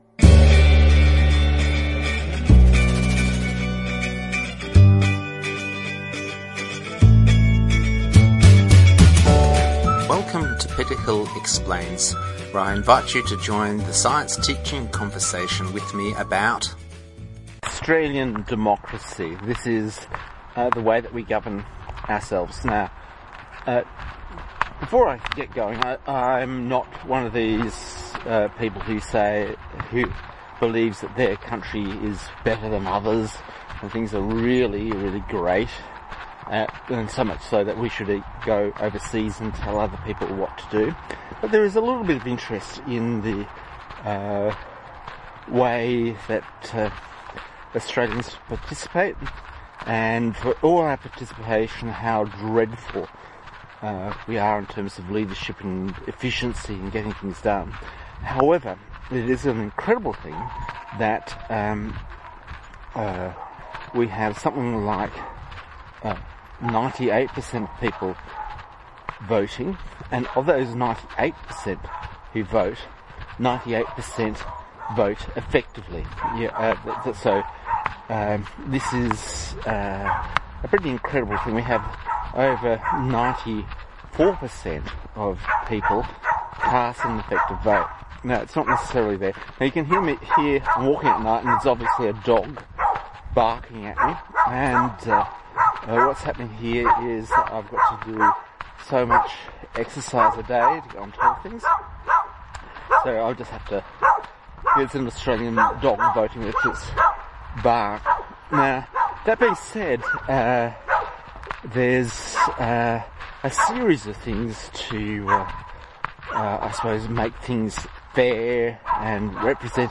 Again, the crunch while I walk. But who would have guessed that we have bumbled our way into 98% of people voting?